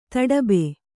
♪ taḍabe